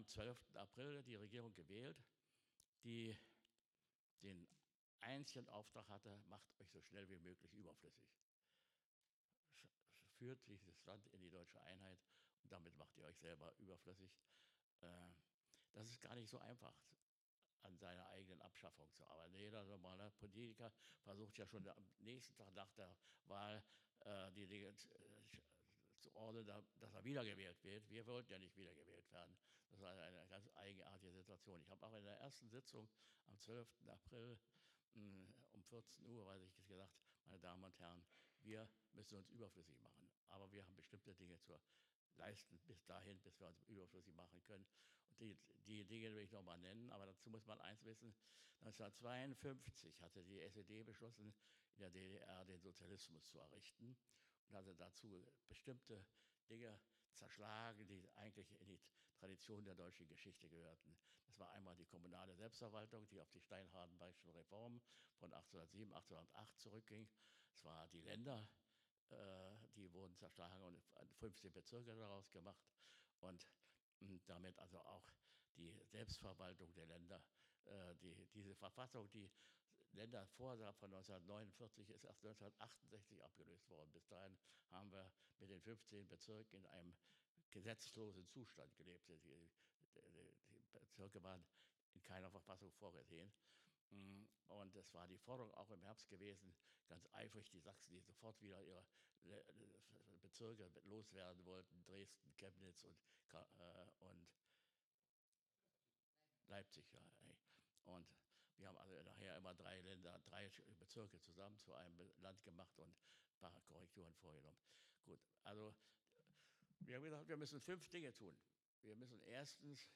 Rede von Lothar de Maizière Teil I